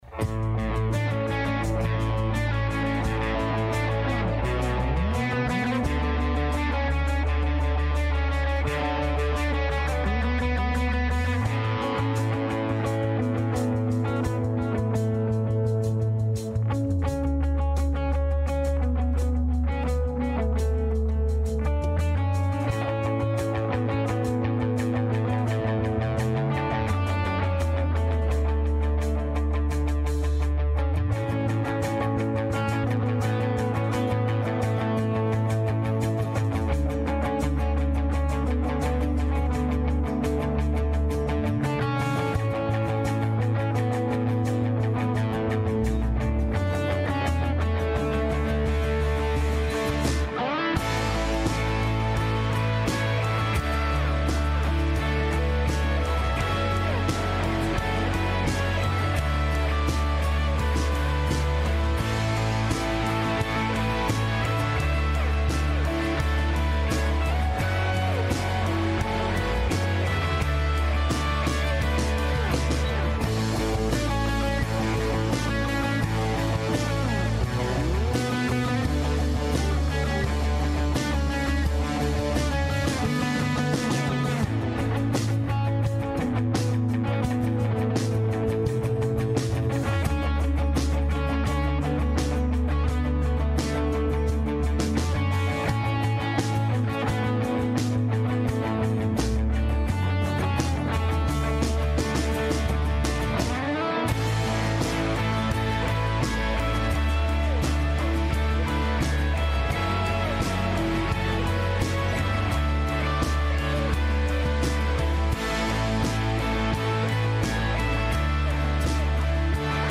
Westgate Chapel Sermons Who Will Save Us?